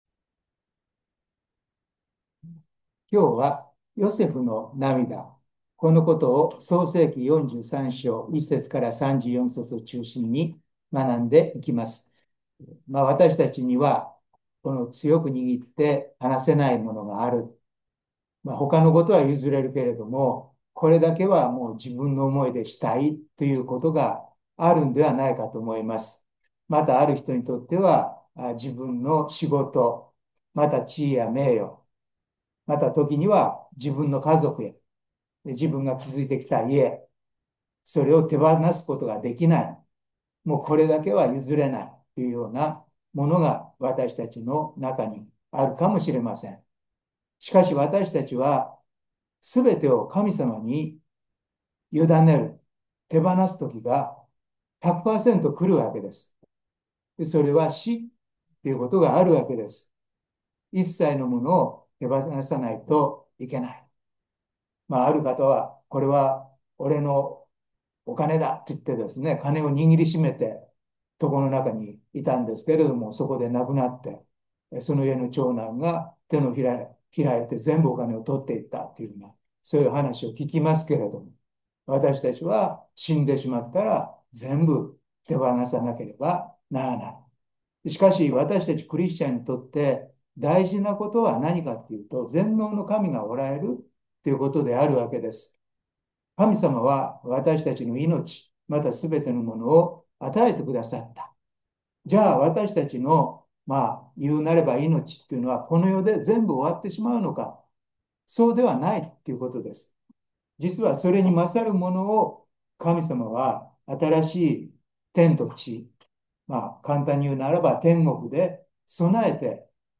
2025/7/9 聖書研究祈祷会